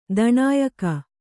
♪ daṇāyaka